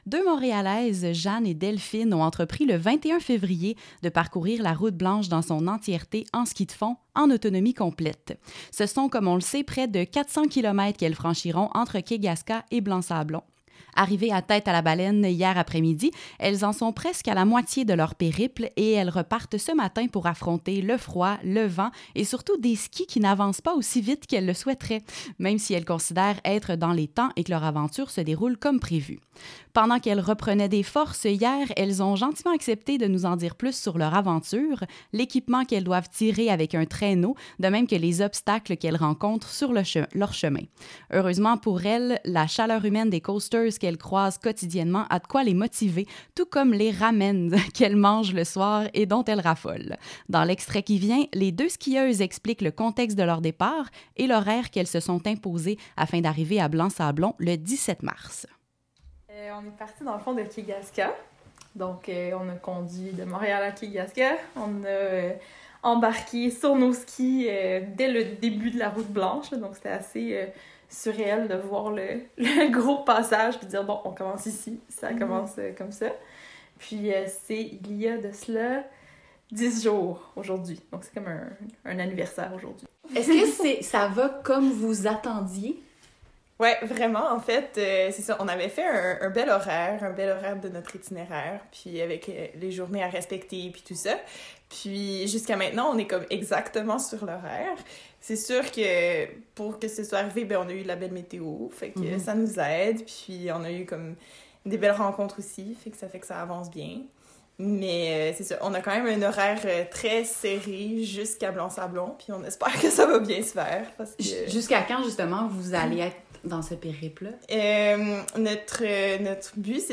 qui ont pris le temps de nous parler de leur aventure au cours d’un ravitaillement effectué à Tête-à-la-Baleine.